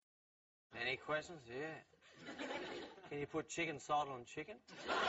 Category: Comedians   Right: Personal
Tags: comedy laughs haha funny lol carl barron